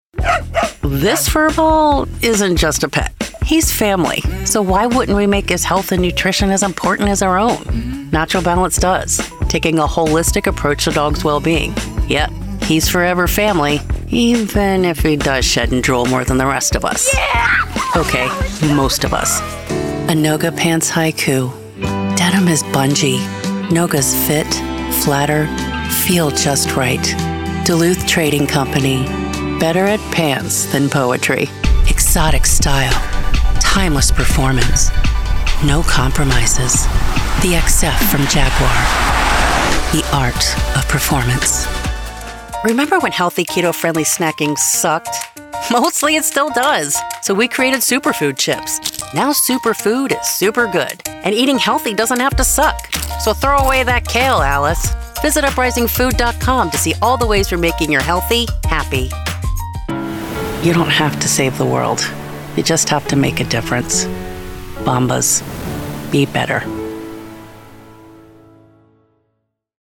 Commercial Demo
English-Mid Atlantic
Middle Aged